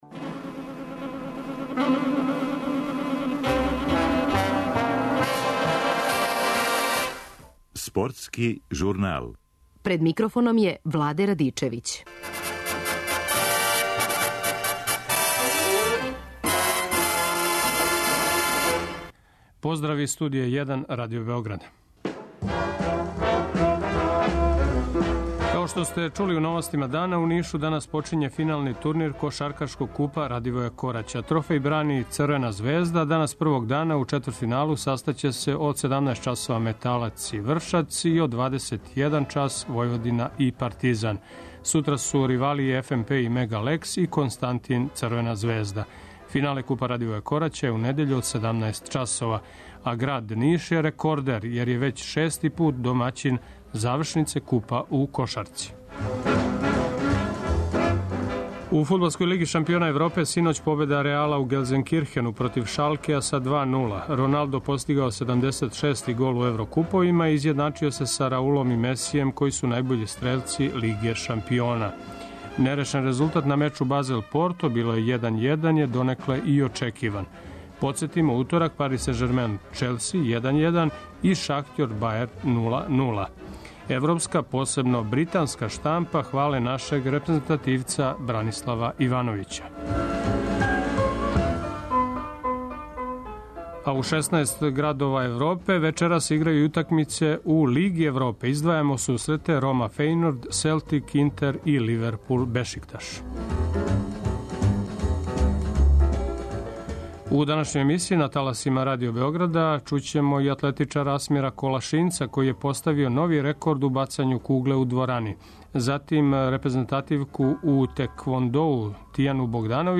У емсији ћемо чути и атлетичара Асмира Колашинца, који је поставио нови рекорд у бацању кугле у дворани, затим теквондисткињу Тијану Богдановић и тренере Партизана и Црвене звезде уочи наставка фудбалског шампионата супер лигаша.